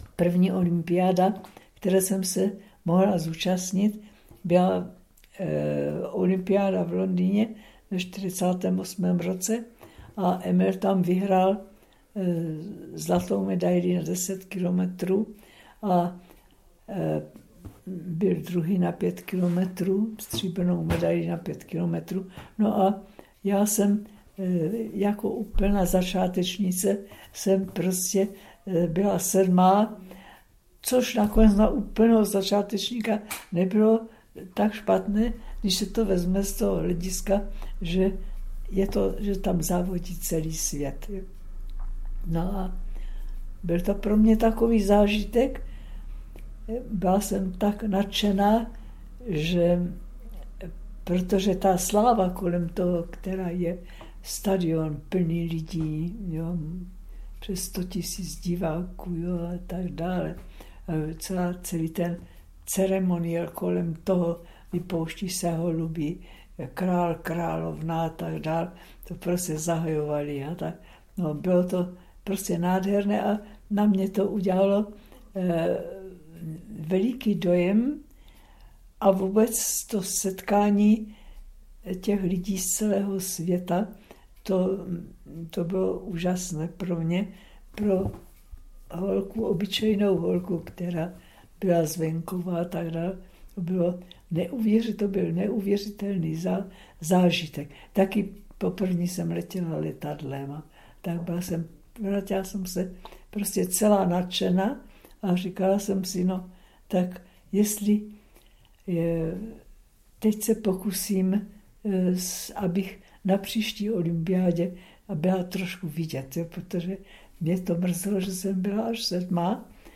ITW 2 - Jeux olympiques de Londres